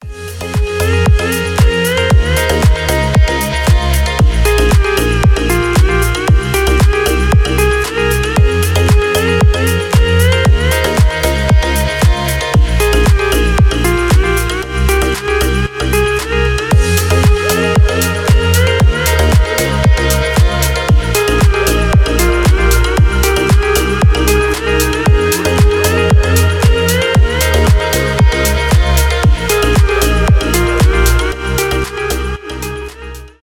без слов , tropical house , танцевальные
приятные , мелодичные